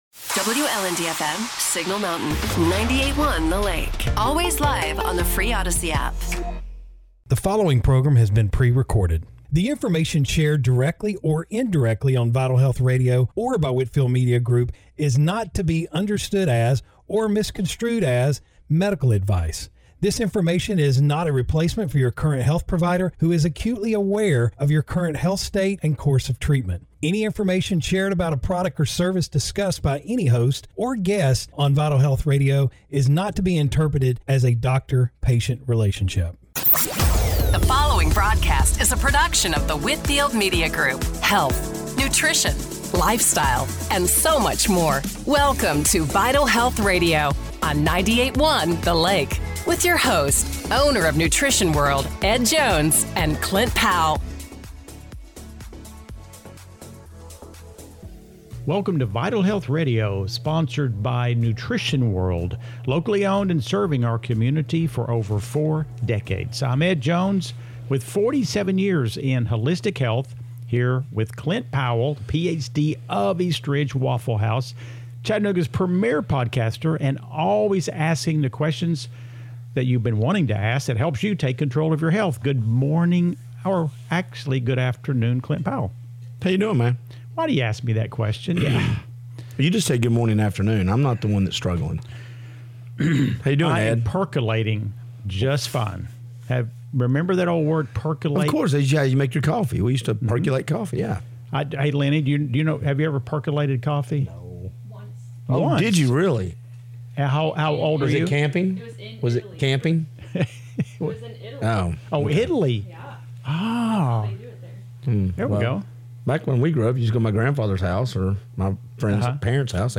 Radio Show / Podcast – April 12, 2026 - Vital Health Radio
Broadcasting from the Nooga Dentistry Studio